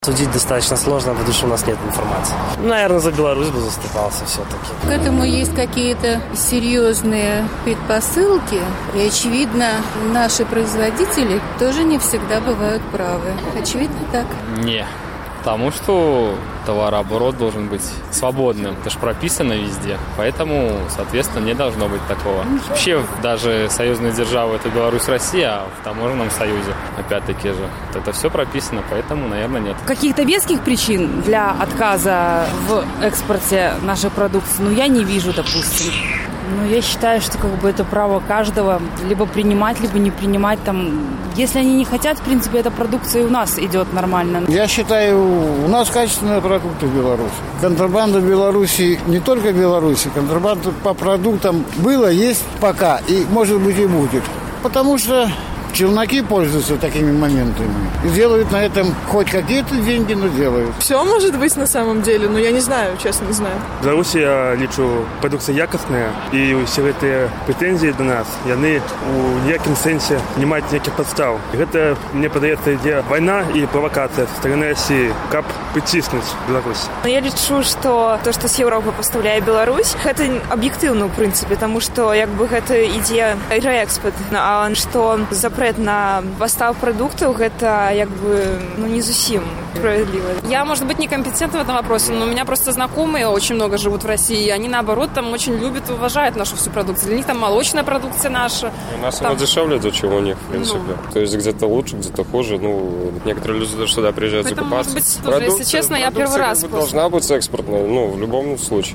Адказваюць менчукі